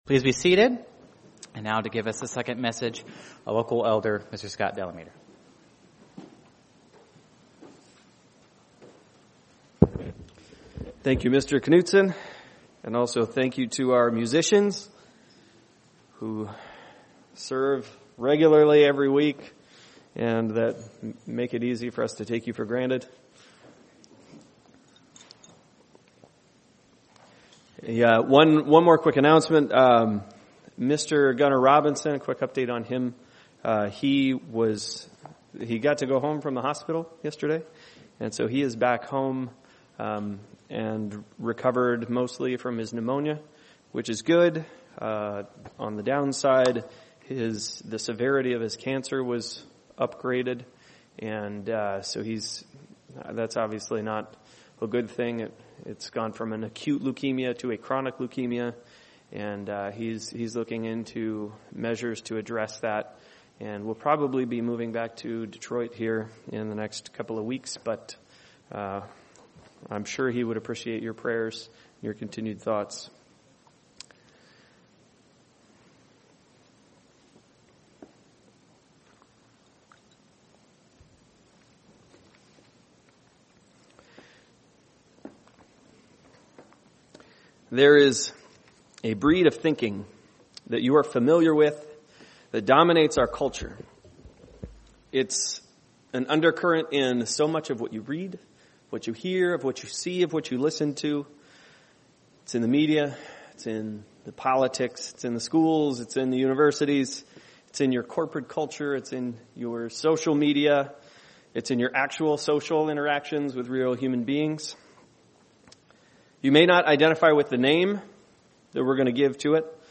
Given in Phoenix East, AZ
He reasons with His people, then and now, UCG Sermon Studying the bible?